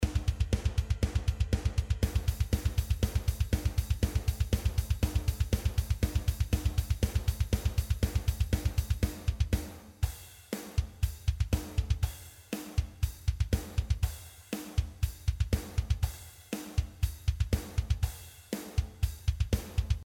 Beurteilung/Hilfe Drum-Mix
Moin Leute, an die Profis unter euch, was und wie, würdet ihr an dem angehängtem Drummix ändern? Stilistisch soll das ganz im Metal angeordnet werden, ein genaues Fachgenre ist mir dabei egal, ich lese mir einfach alles durch und schau mal wie sich welche Einwürfe auswirken.